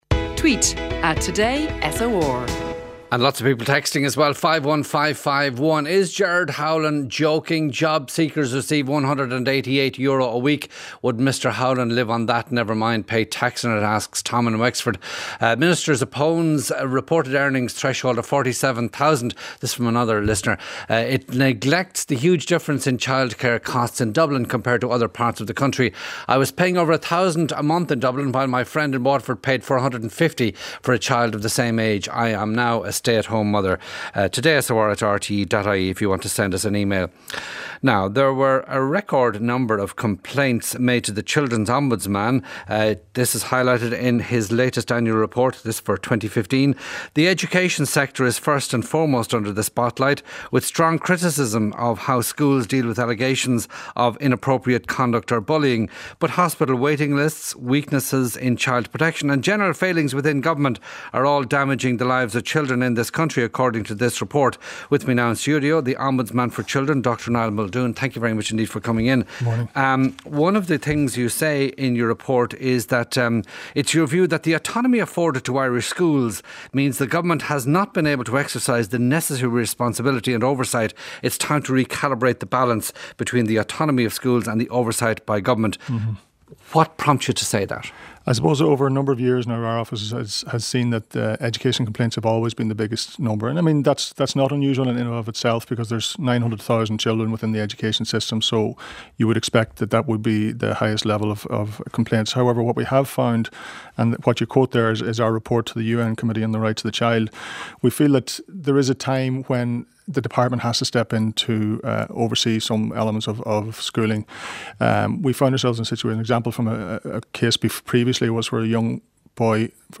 Ombudsman for Children, Dr Niall Muldoon discusses some of the cases detailed in Annual Report 2015 on the Today with Sean O’Rourke Show